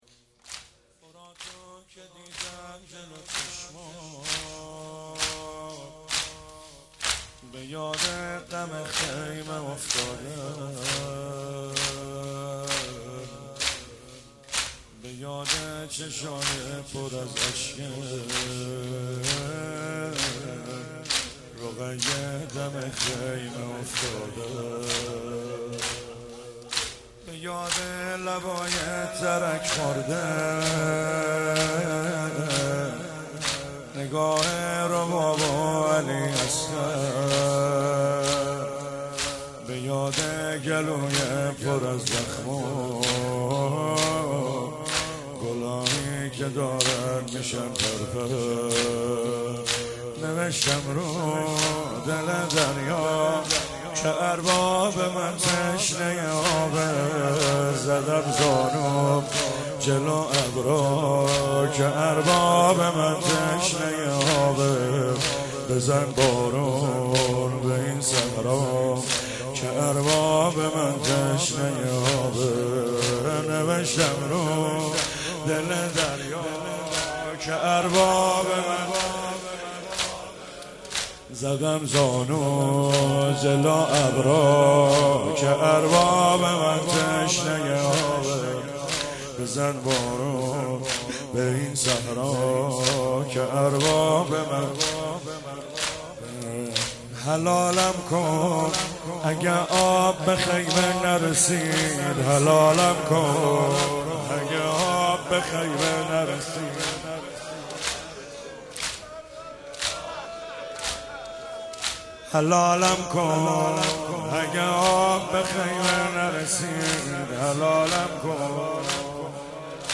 چهاراه شهید شیرودی حسینیه حضرت زینب (سلام الله علیها)